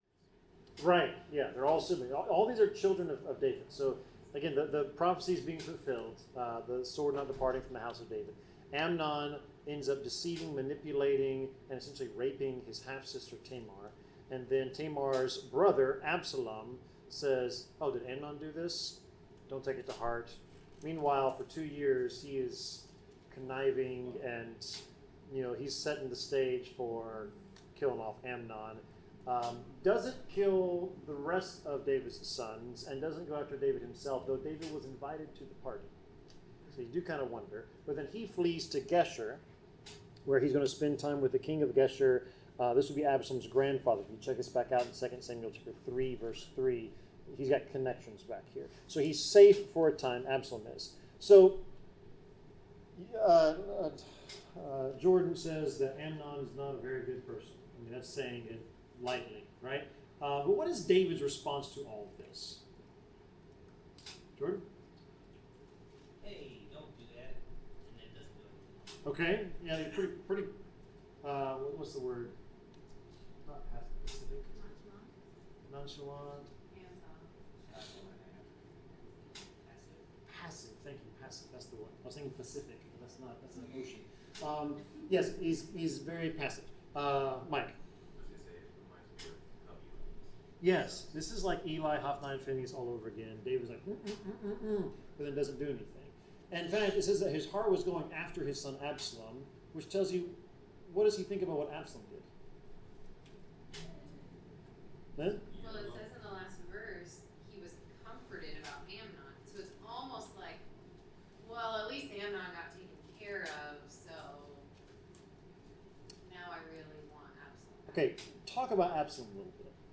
Bible class: 2 Samuel 14-15 (Absalom’s Ascension)
Service Type: Bible Class